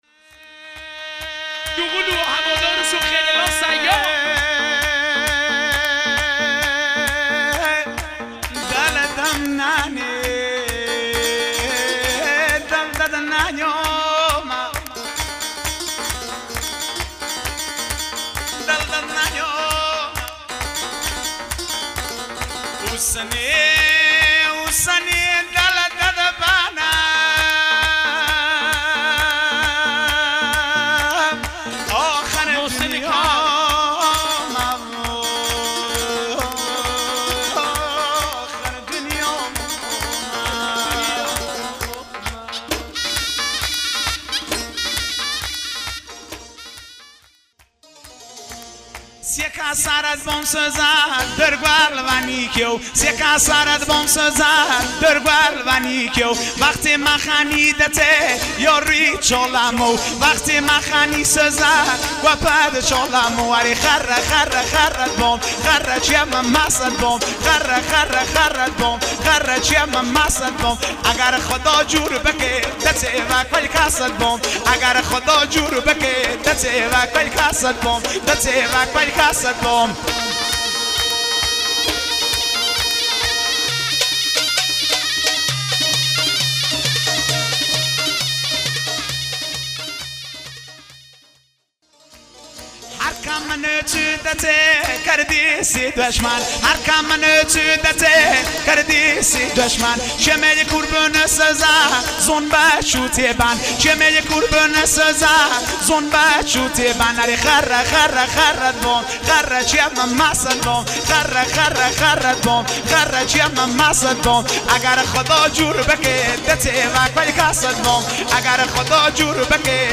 موزیک شاد لکی ویژه عروسی